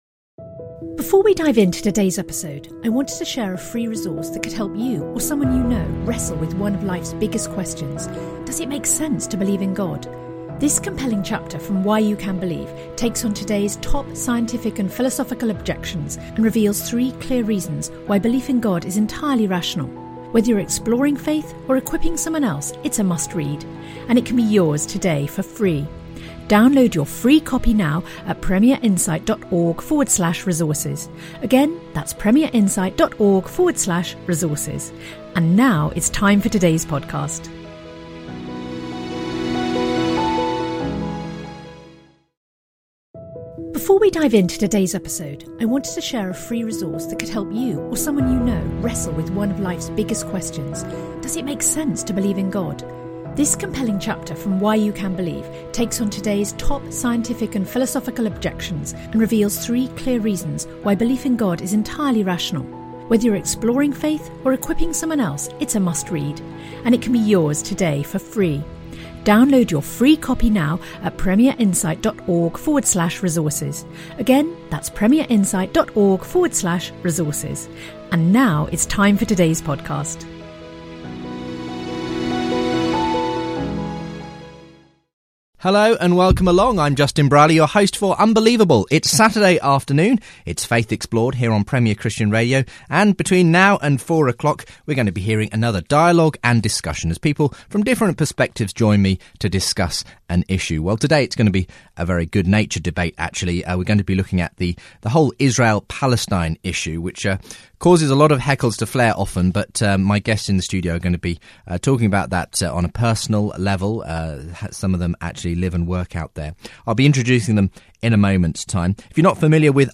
For more faith debates